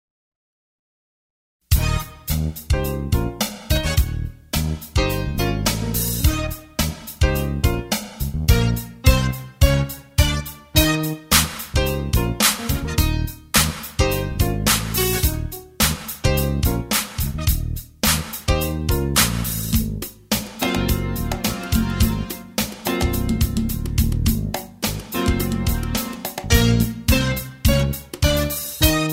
▪ The full instrumental track